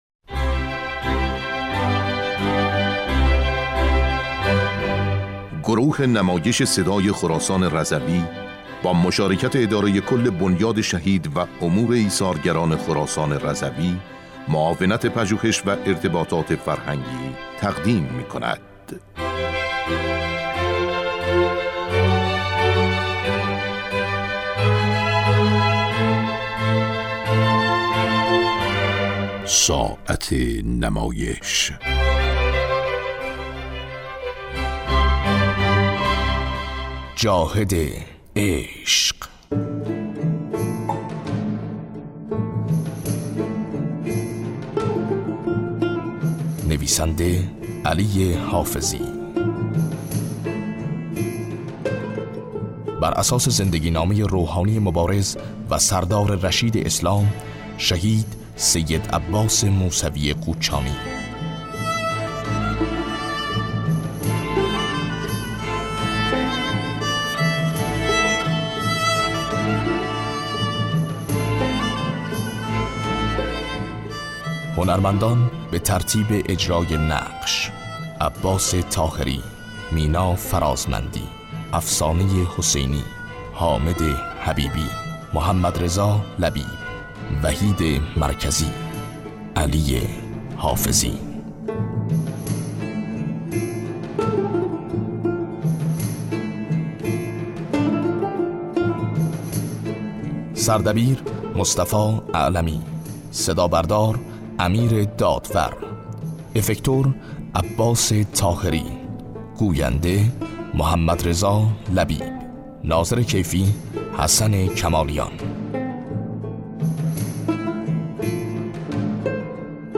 نمایشنامه رادیویی جاهد عشق